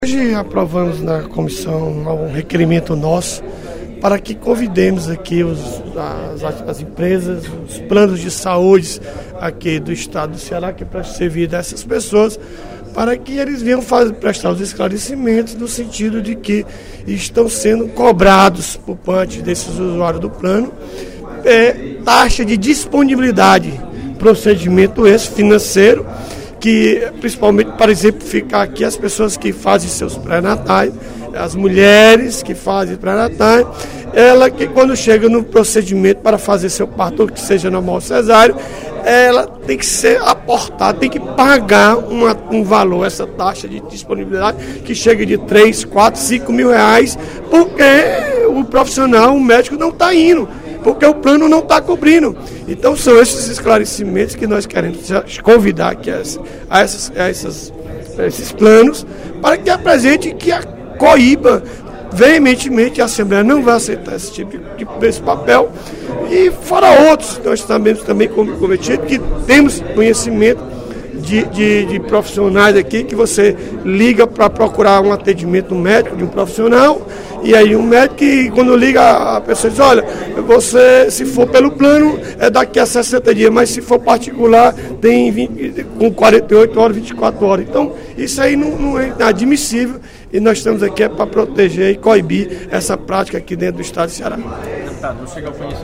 O deputado Odilon Aguiar (PMB) anunciou, durante o primeiro expediente da sessão plenária desta quinta-feira (25/02), que a Comissão de Defesa do Consumidor da Assembleia Legislativa vai realizar audiência pública no dia 9 de março, às 14h. O debate  vai abordar a cobrança de taxa de disponibilidade por profissionais médicos que realizam procedimentos de pré-natal e partos.